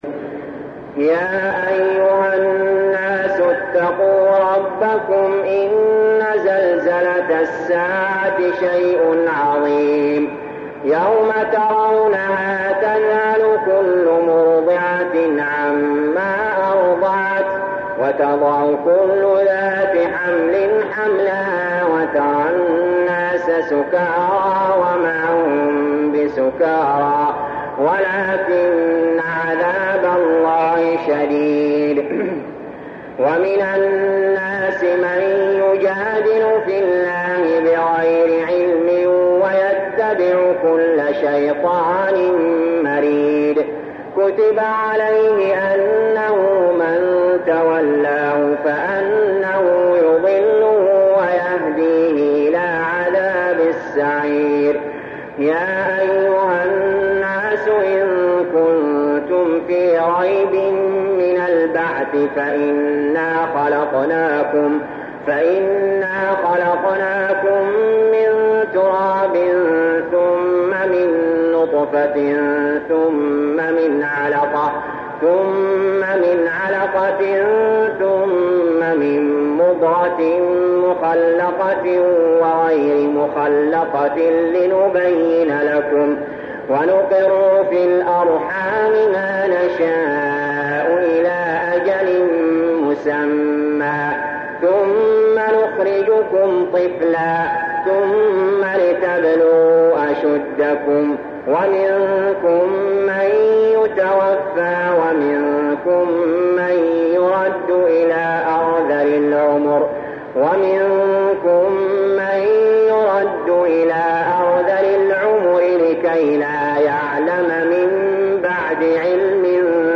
المكان: المسجد الحرام الشيخ: علي جابر رحمه الله علي جابر رحمه الله الحج